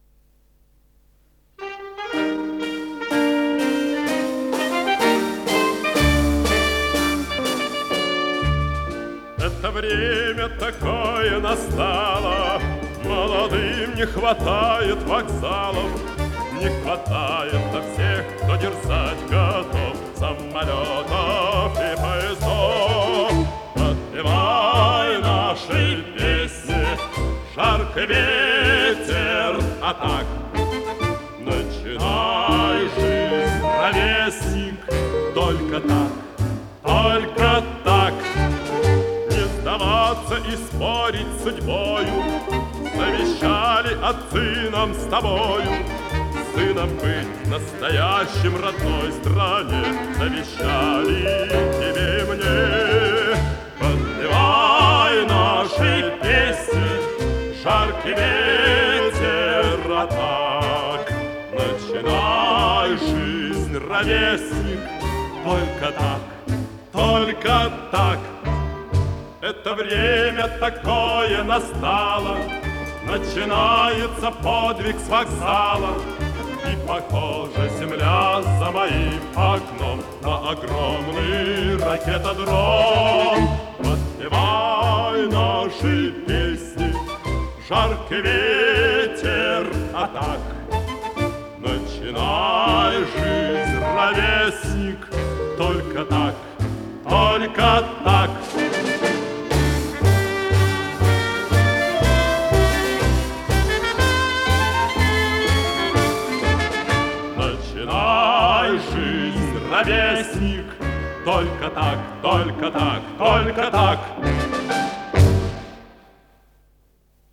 с профессиональной магнитной ленты
АккомпаниментИнструментальный ансамбль
Скорость ленты38 см/с